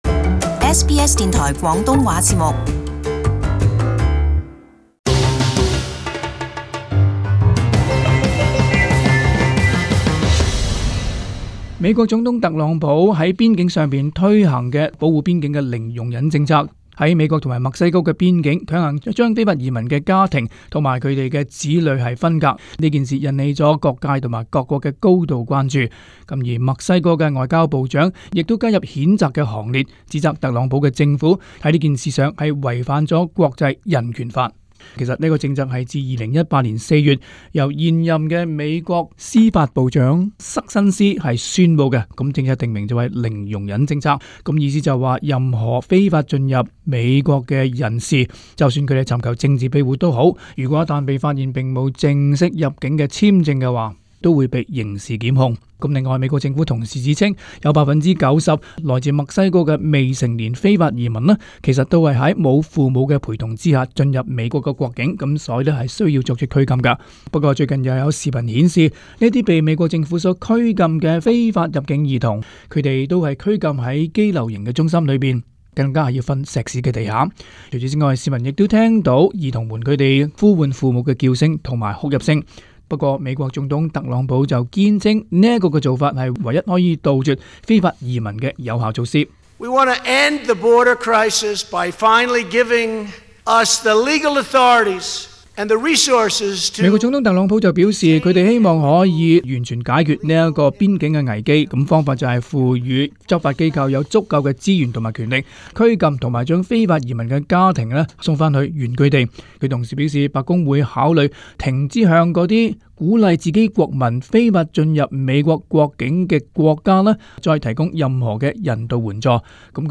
【時事報導】特朗普最終容許非法入境兒童與家人一同拘禁